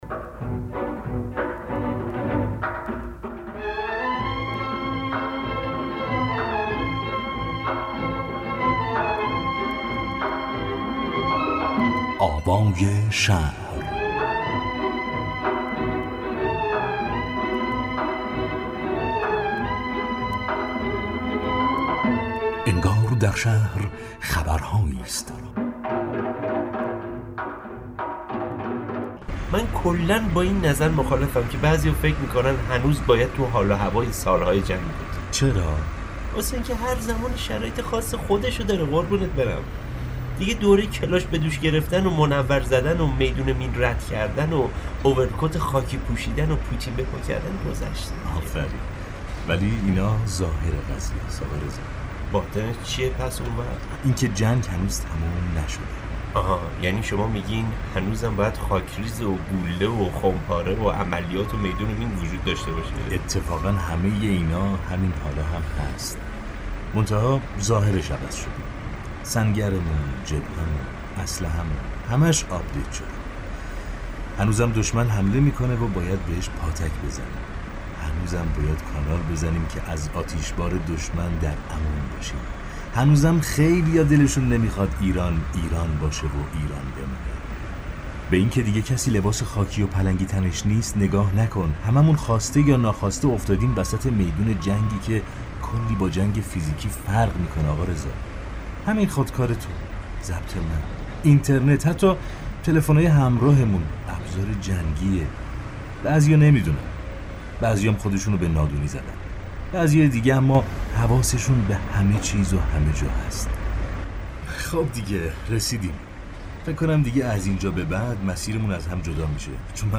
در ویژه‌ برنامه رادیویی «قطار انقلاب» کاری از گروه چند رسانه ای پایگاه «آوای جامعه» دو نسل از انقلاب در خیابان‌های شهر در کنار هم قدم می زنند و از روزهای پرشور گذشته تا نگاه امروزشان به مسیر انقلاب می‌گویند.